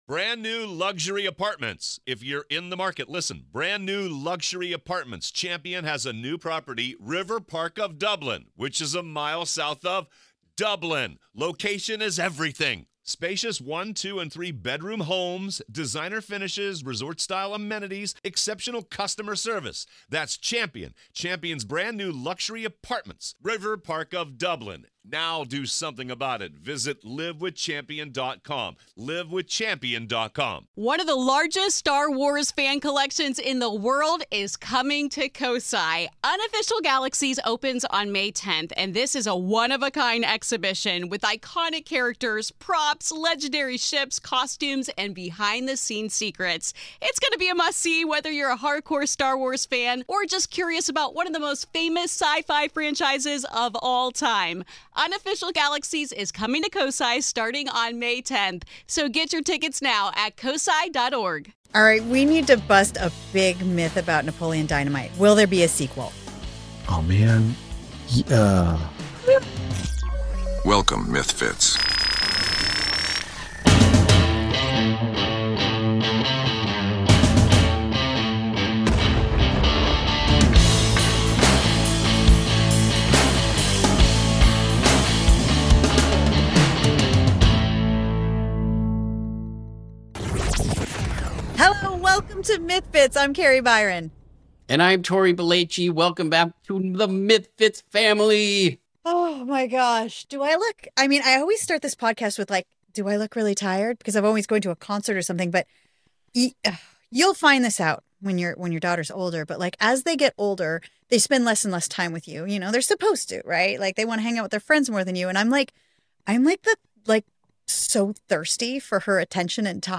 Jon Heder joins Kari and Tory to clear up some of the internet’s biggest myths about Napoleon Dynamite.